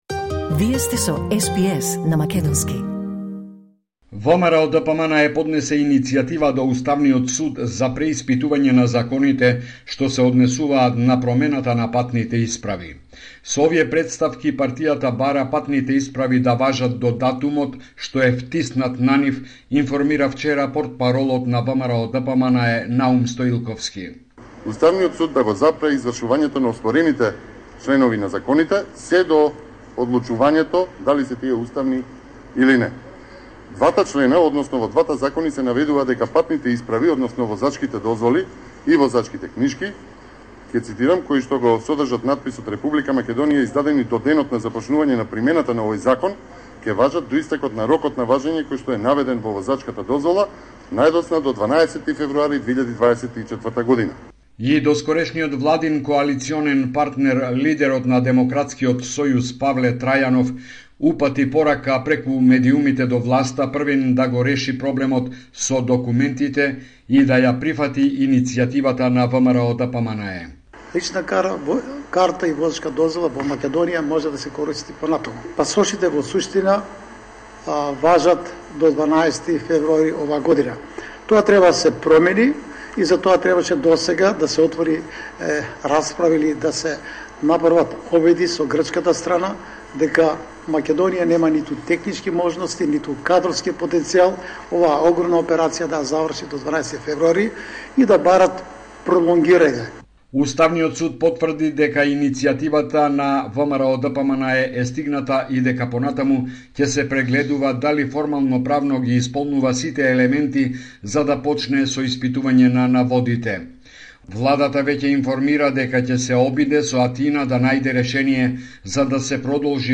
Homeland Report in Macedonian 17 January 2024